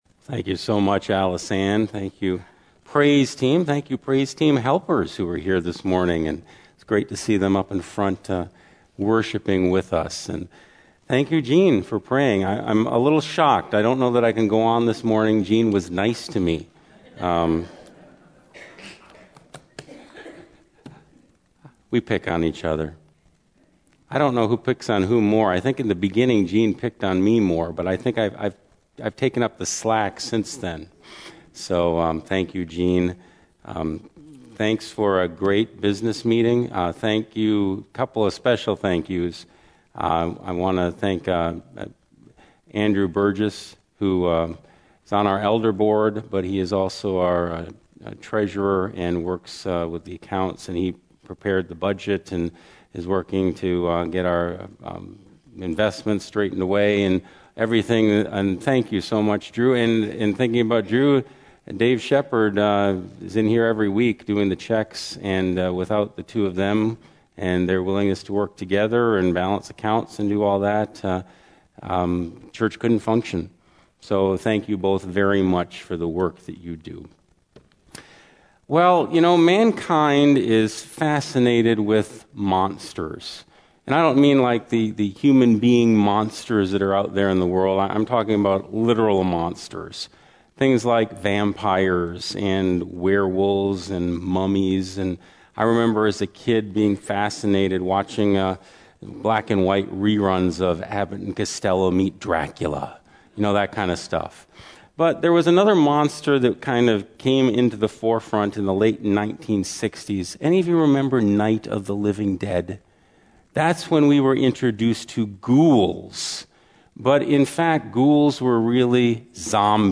First Baptist Church Sermons